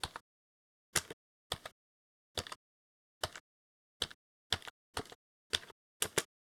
Keyboard_1_slow_tapping_05
Keyboard_1_slow_5.wav